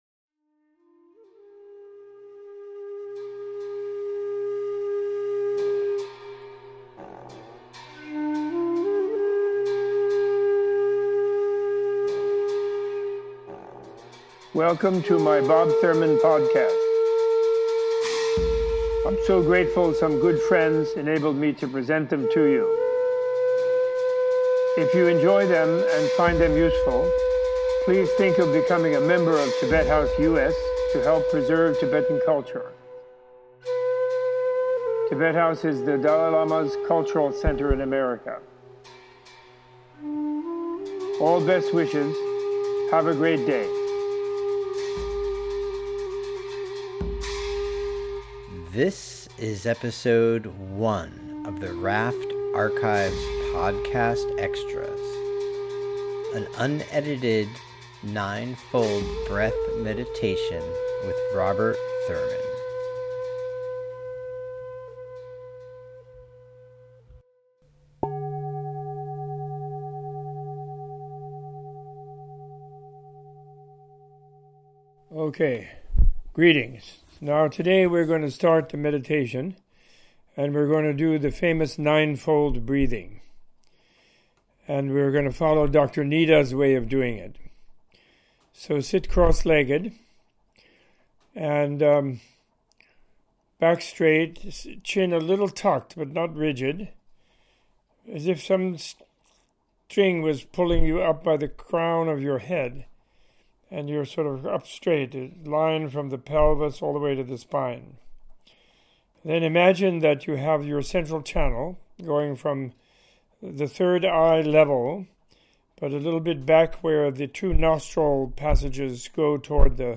In this podcast extra Professor Robert A.F. Thurman leads an extended Nine Fold Purification Breath Meditation from the Yuthok Nyingthig as taught by Dr Nida Chenagtsang.